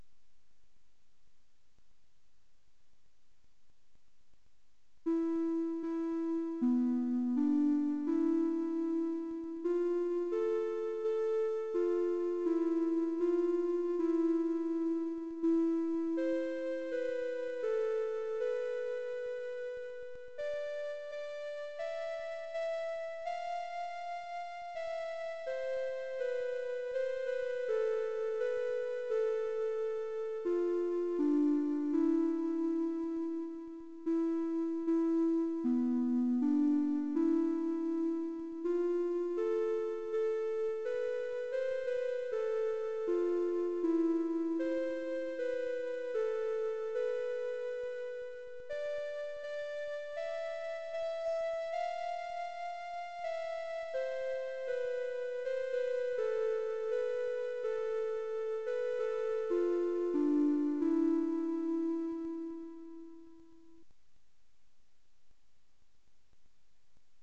演奏1 mp3